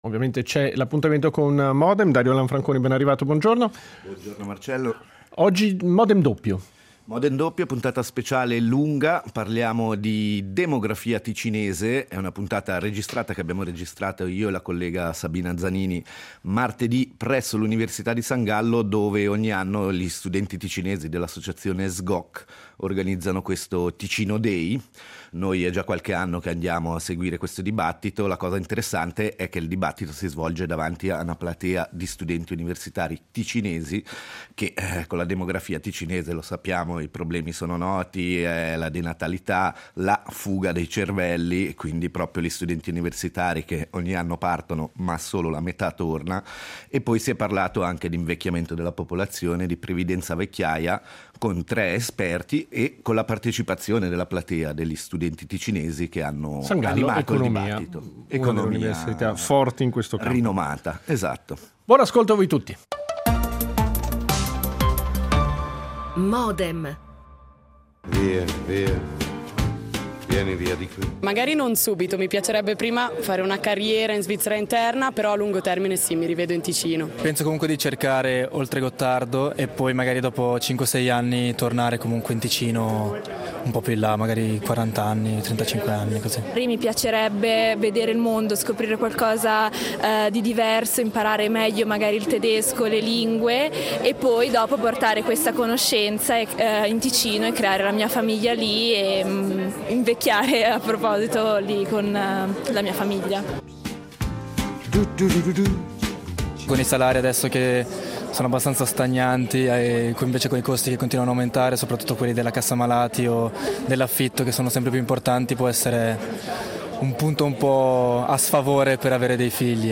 Dibattito all’Università di San Gallo in occasione dell’annuale Ticino Day, con la partecipazione degli studenti ticinesi